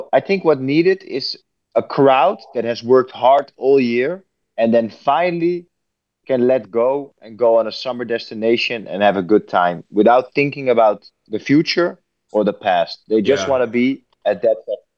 Tom smo prilikom intervjuirali R3HAB-a koji je oduševljen suradnjom s Jasonom Derulom.